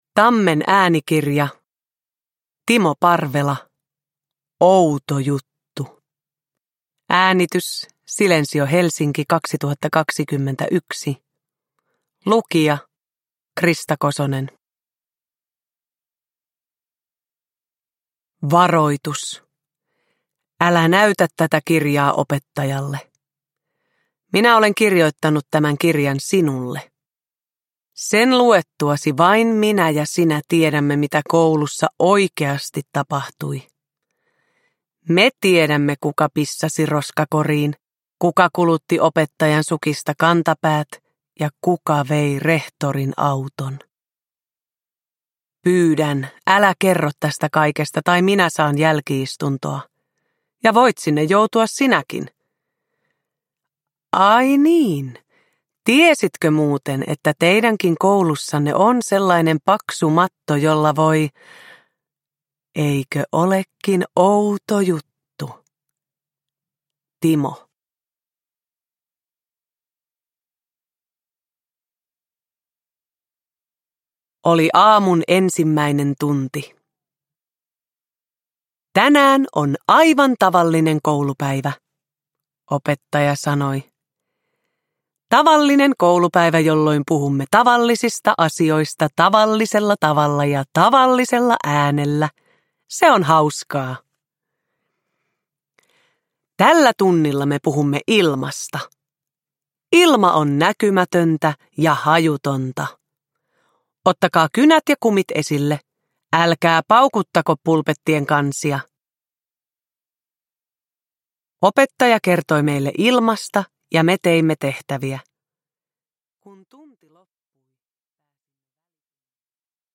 Outo juttu – Ljudbok – Laddas ner
Uppläsare: Krista Kosonen